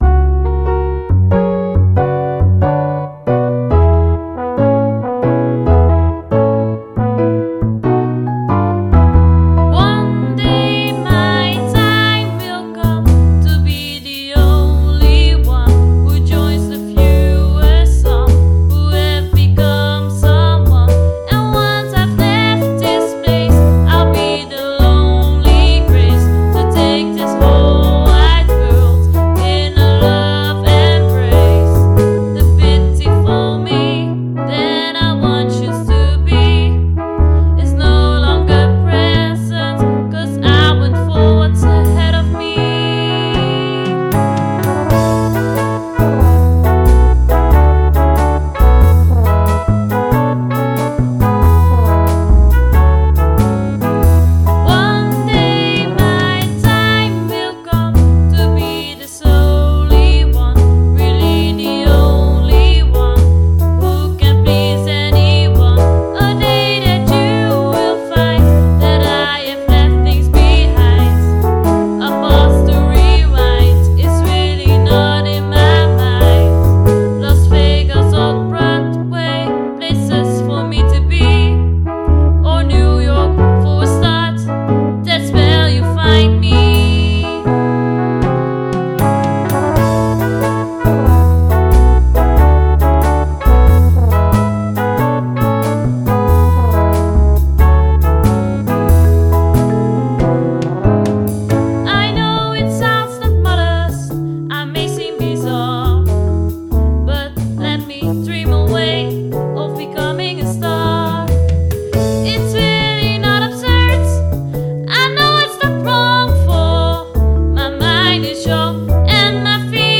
Engelstalig, showjazz)